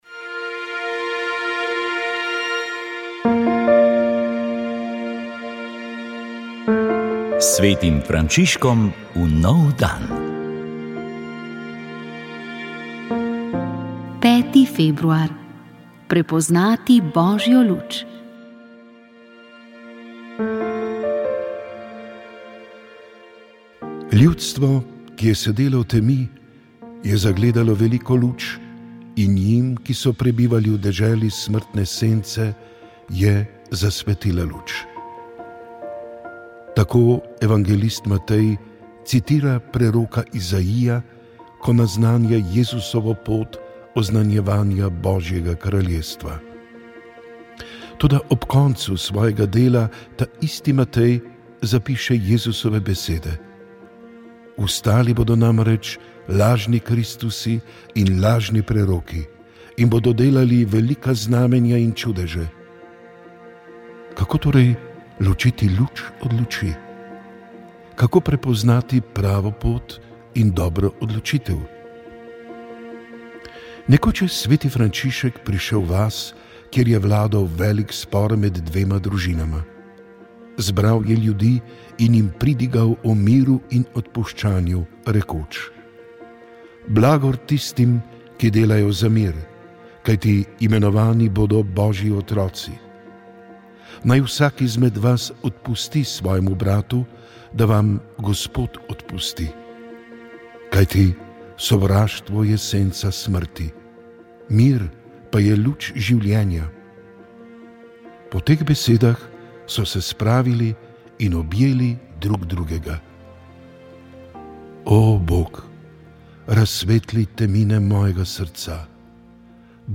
V oddaji Utrip Cerkve v Sloveniji ste slišali posnetek nagovora, ki ga je imel predsednik Slovenske škofovske konference nadškof Stanislav Zore na praznik Brezmadežne, ko smo tudi sklenili leto sv. Jožefa in devetdnevnico za zdravje in konec epidemije.